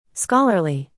Learn the pronunciation: